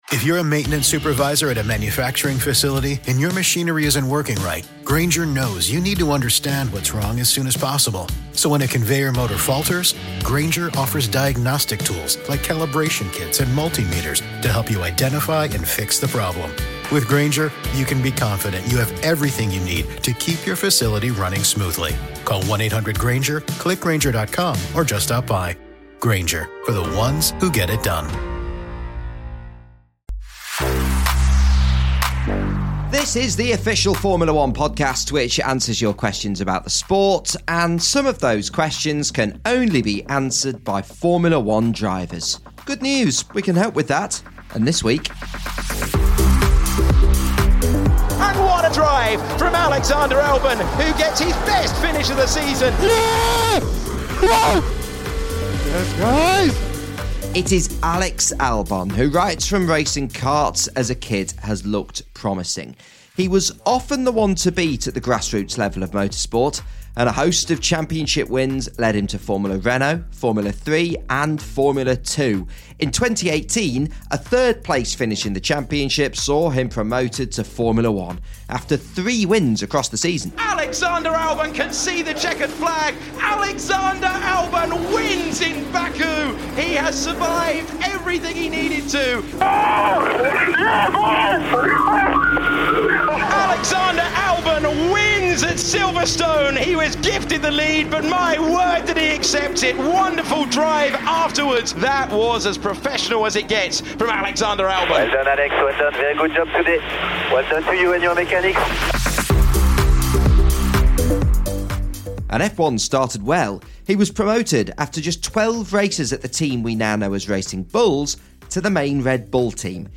in the Formula 1 paddock